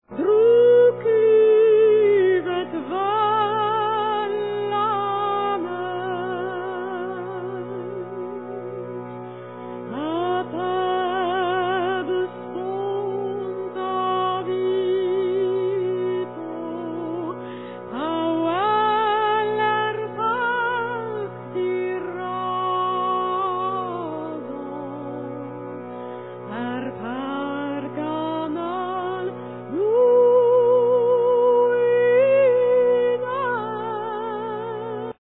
French and Breton folk songs